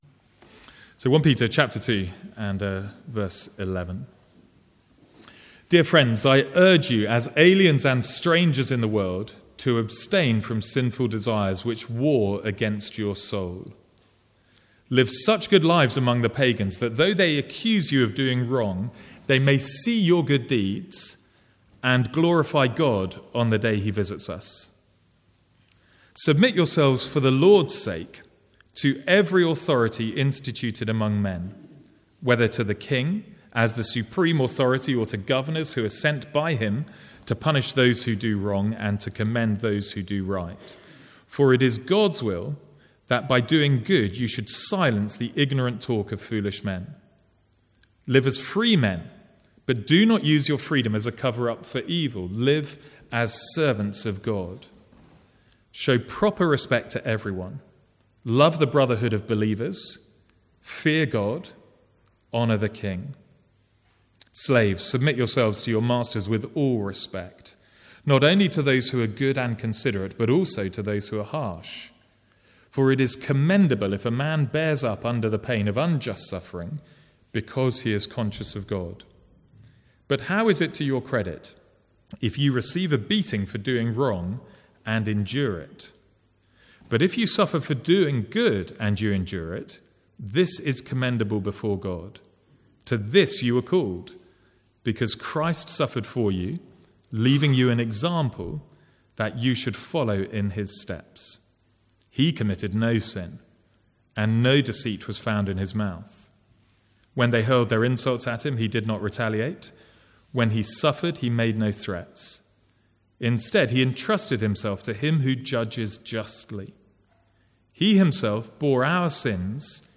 Sermons | St Andrews Free Church
From the Sunday morning series in 1 Peter.